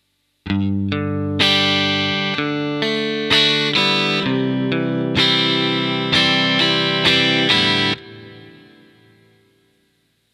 Here, we’ve added a 2nd note after each root to develop our bassline further.
For example, in the final bar we play the root and then the Maj 3rd of the C chord.